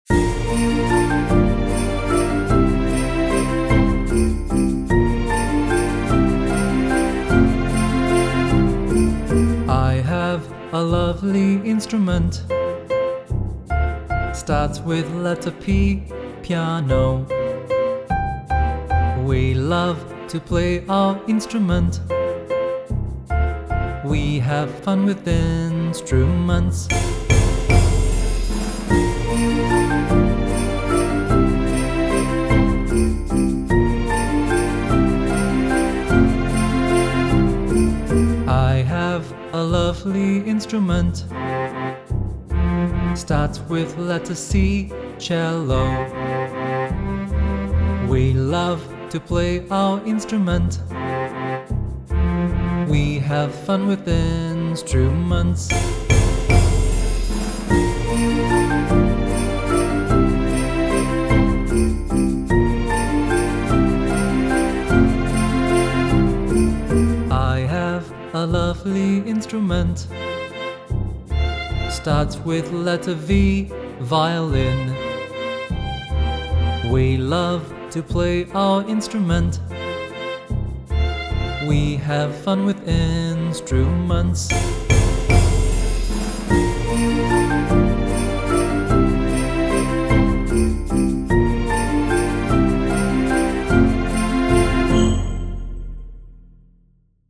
Singing
Fast